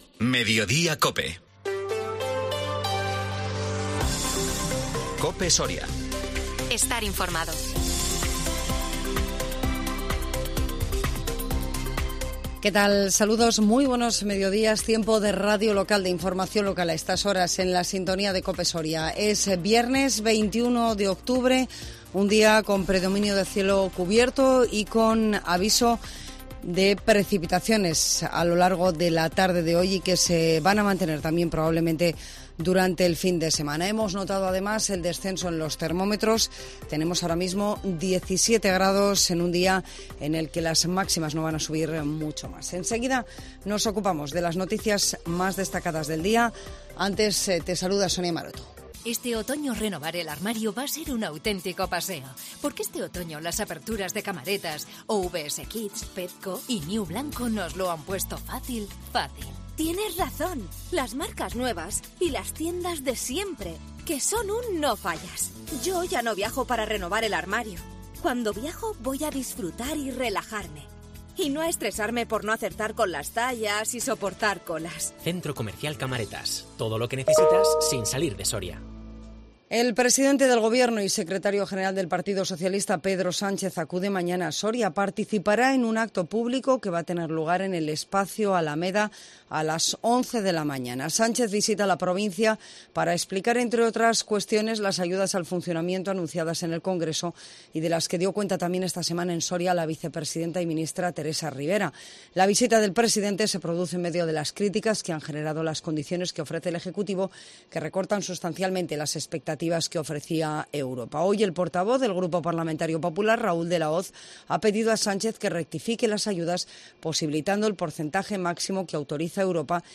INFORMATIVO MEDIODÍA COPE SORIA 21 OCTUBRE 2022